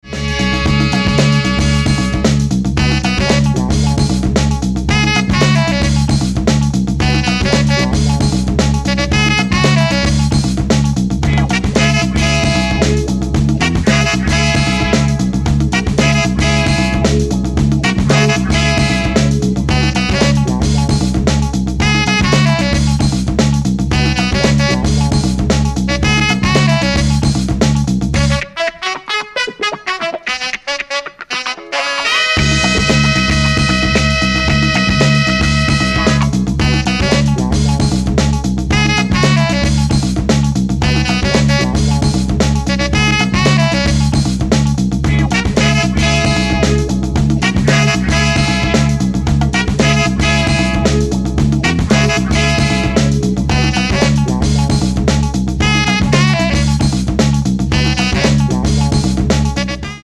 classy cover